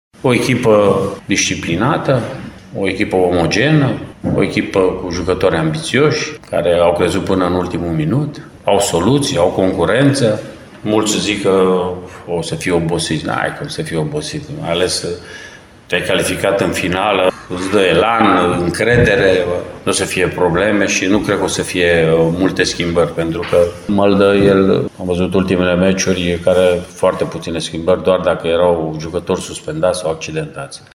Antrenorul Mircea Rednic a vorbit în termeni laudativi despre echipa pregătită de fostul său coleg și elev de la Rapid, Marius Măldărășanu.
26-apr-8.30-Rednic-despre-FC-Sibiu.mp3